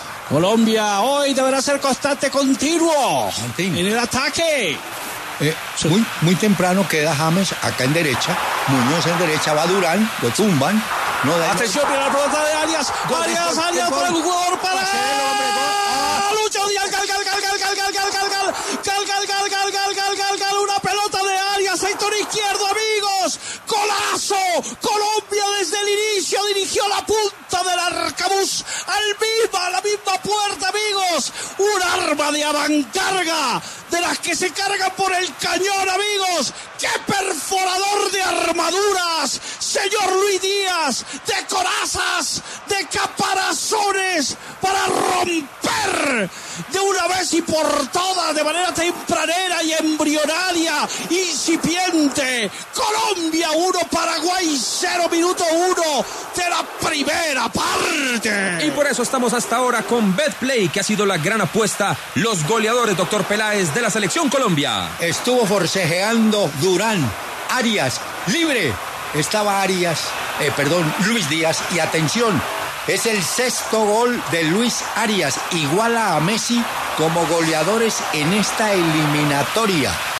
“¡Perforador de armaduras!”: Emocionante narración de Martín De Francisco al golazo de Luis Díaz
Martín De Francisco narró con todo el sentimiento el golazo de Luis Díaz ante Paraguay.
Con un gol al minuto 1, Colombia le gana parcialmente a Paraguay en la jornada 14 de las Eliminatorias Sudamericanas, partido que se disputa en el estadio Metropolitano de Barranquilla y que es narrado por Martín De Francisco y analizado por Hernán Peláez, periodistas de W Radio.